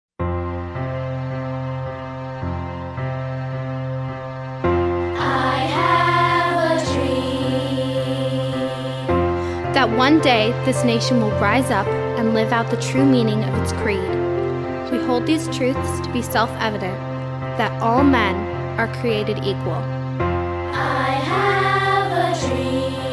vocal    instrumental